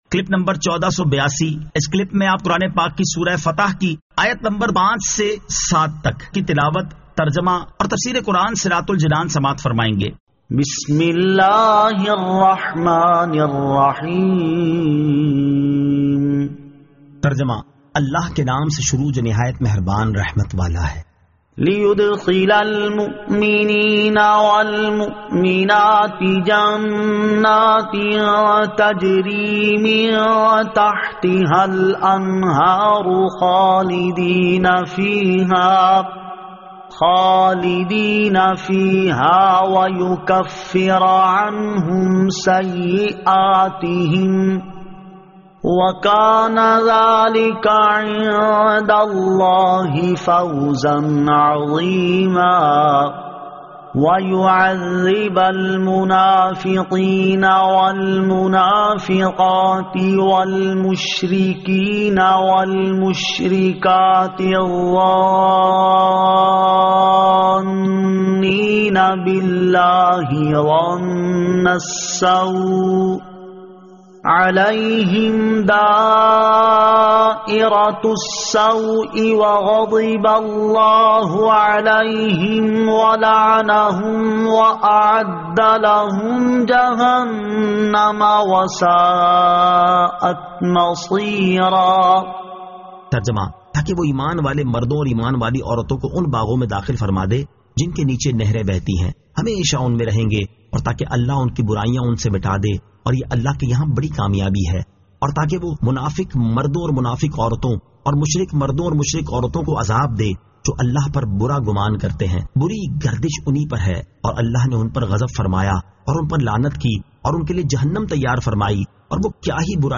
Surah Al-Fath 05 To 07 Tilawat , Tarjama , Tafseer